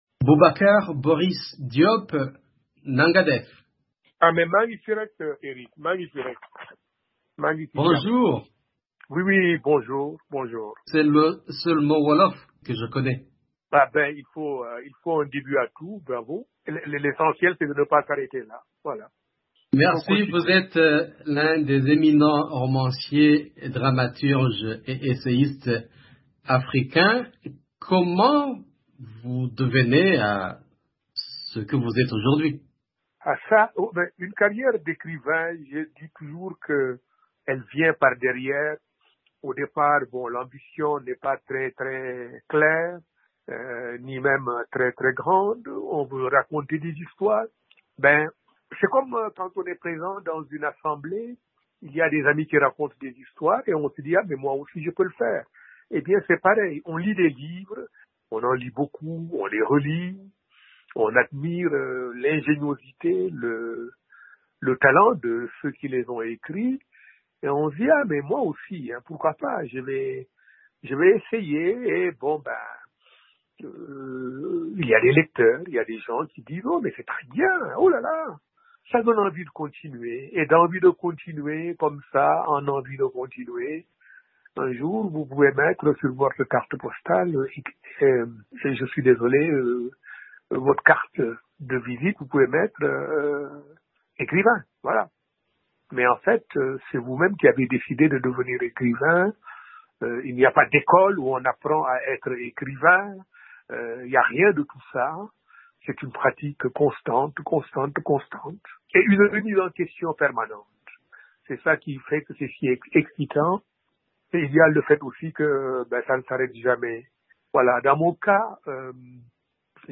Boris Diop a reçu le 24 octobre 2022 le Neustadt, le prix international de littérature qui lui a été décerné dans l'Etat américain de l'Oklahoma. Dans un entretien exclusif à VOA Afrique, Boris raconte ce que le prix qu’il a reçu, équivalent du Nobel de littérature, représente pour lui.